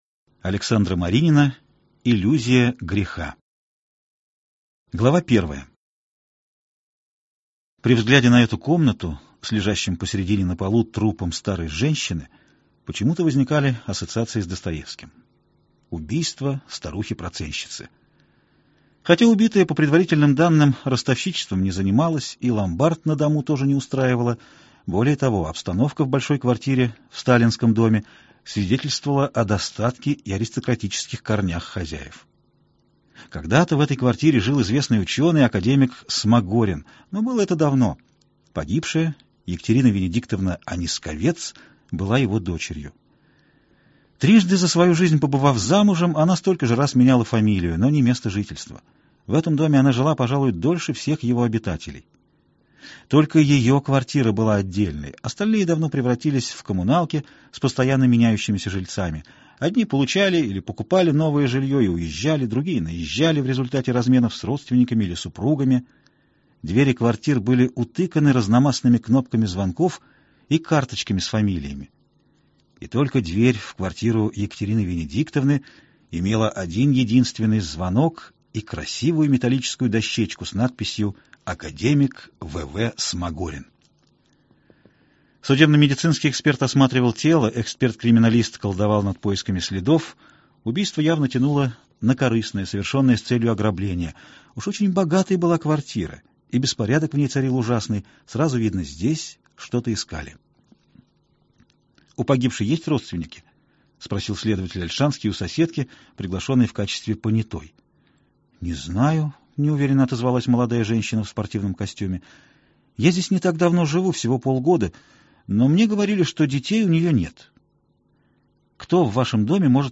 Аудиокнига Иллюзия греха. Часть 1 | Библиотека аудиокниг